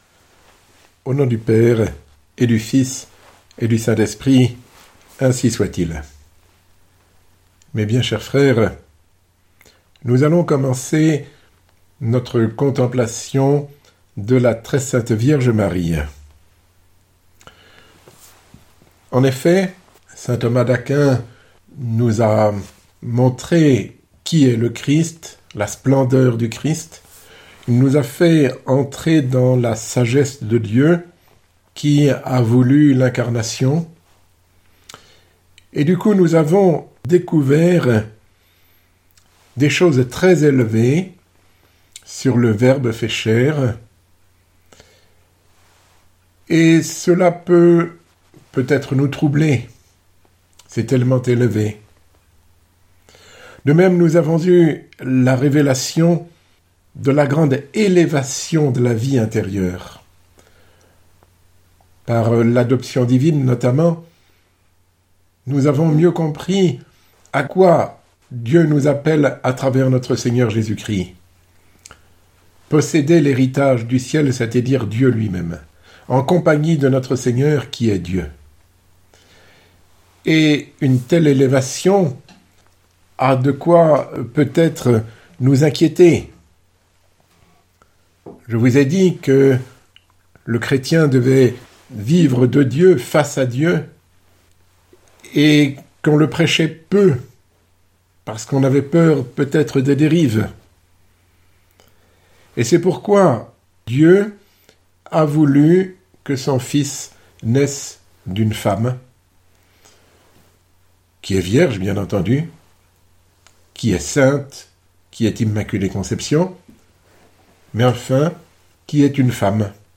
Sermon ~ La sainteté et la virginité de la sainte Vierge Marie